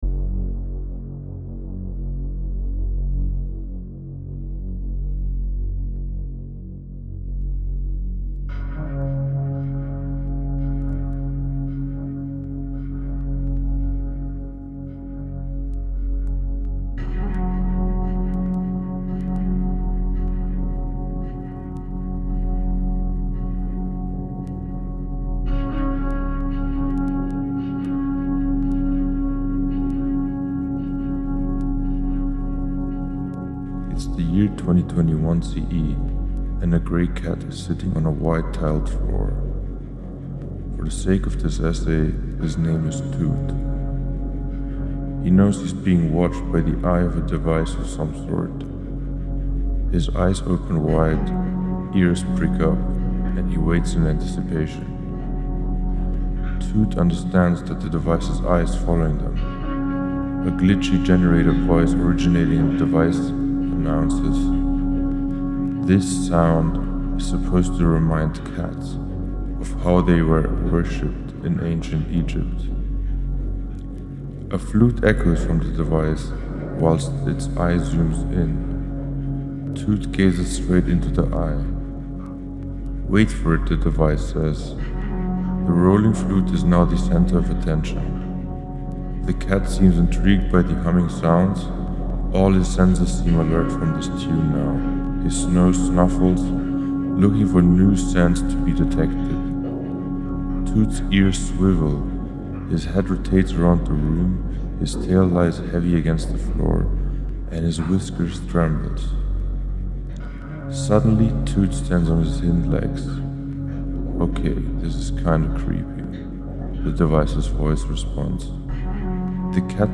Genre: Spoken Word/Ambient/Experimental